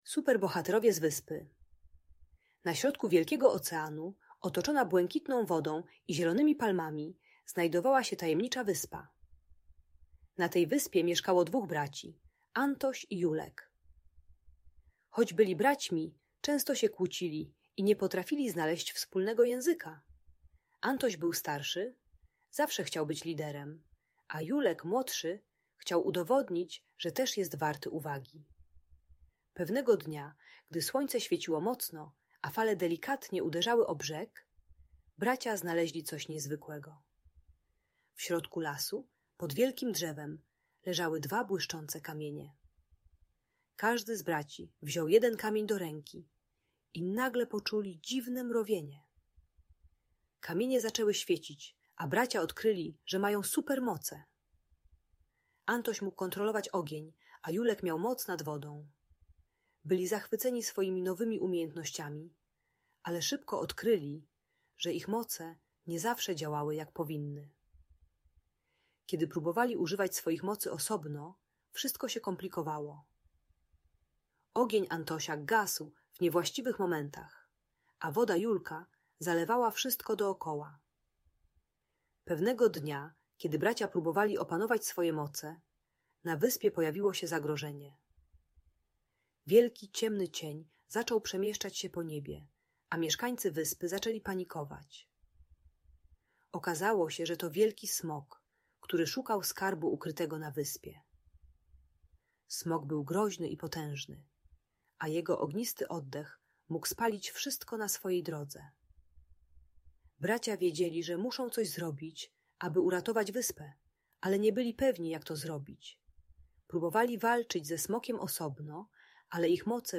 Superbohaterowie z Wyspy - Rodzeństwo | Audiobajka